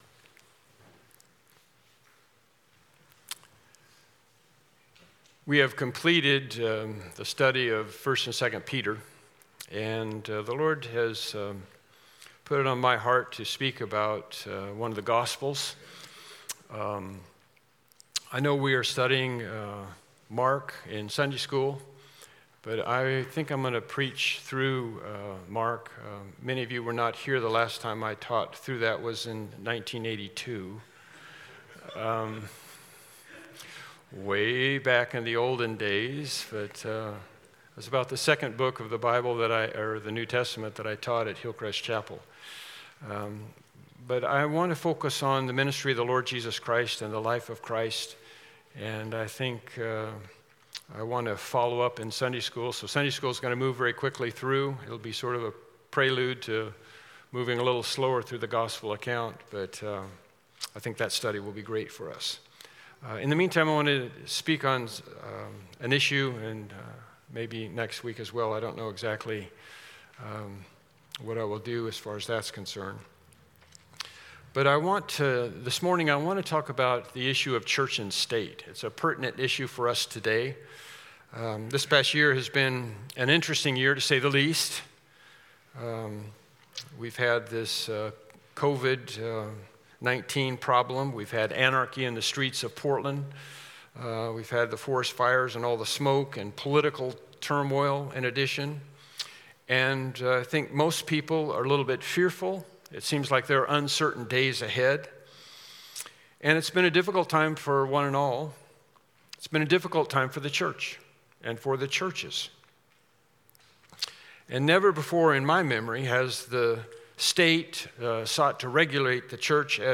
Romans 13:1-7 Service Type: Morning Worship Service Topics: Authority , Government , State « Growing in Grace Lesson 3